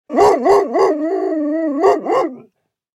جلوه های صوتی
دانلود صدای سگ 6 از ساعد نیوز با لینک مستقیم و کیفیت بالا